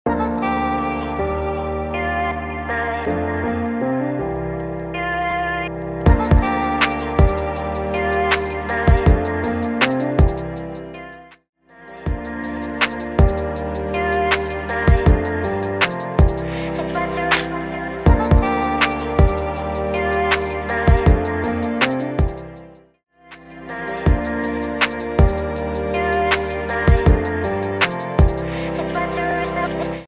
80 BPM
Hip Hop
Smooth Electronic